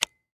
Pistol_Flashlight.wav